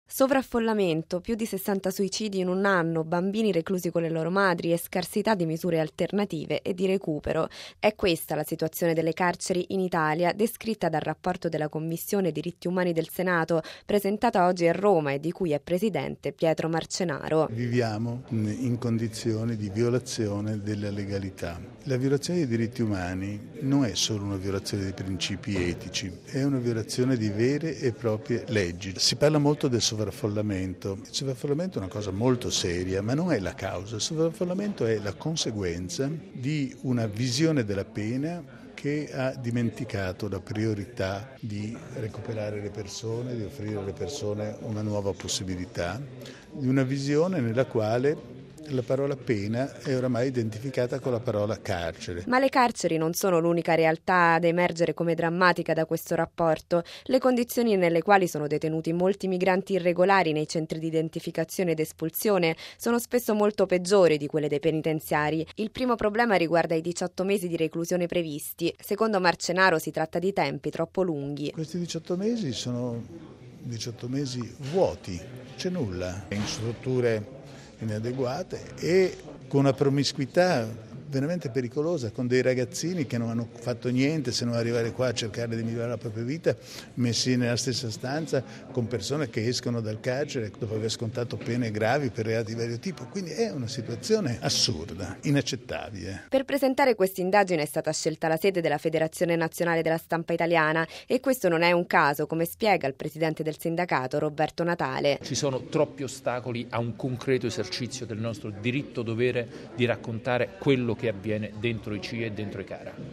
come spiega il presidente del sindacato